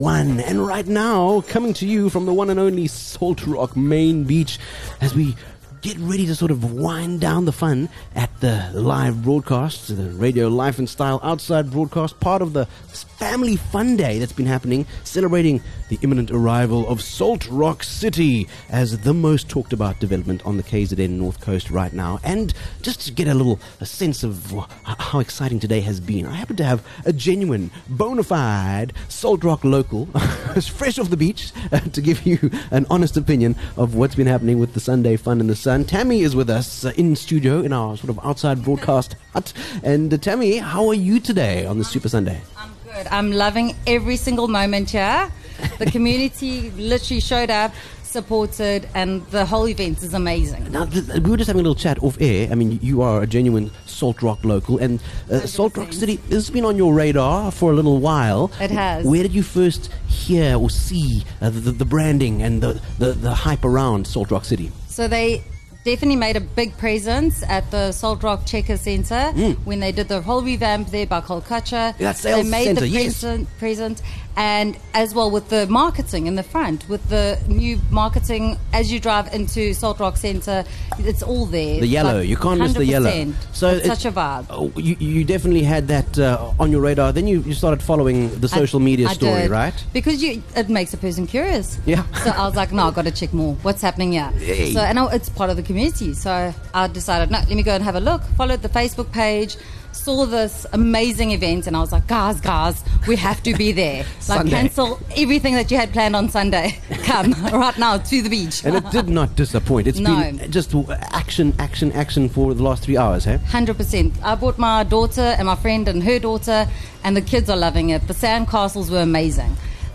23 Feb Live from Salt Rock Main Beach: The Salt Rock City Family Beach Day